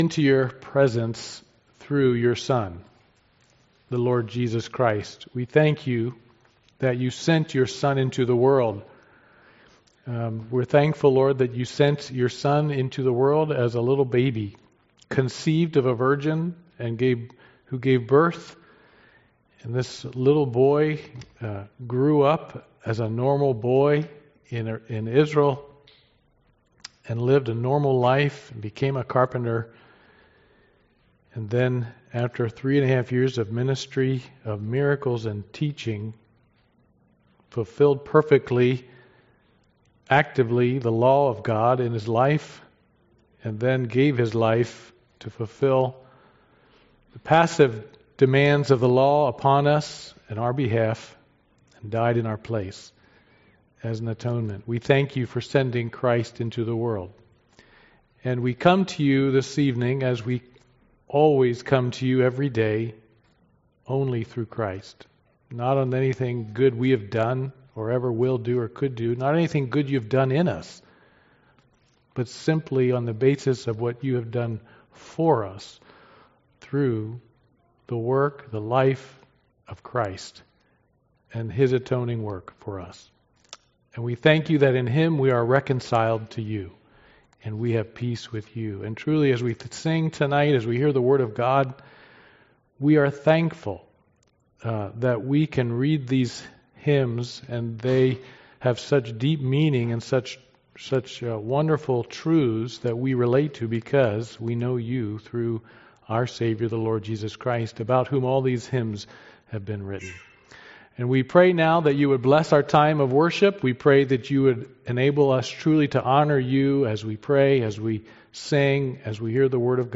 Christmas Service